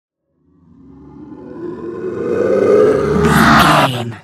Creature riser horror
Sound Effects
In-crescendo
Atonal
scary
ominous
haunting
eerie
roar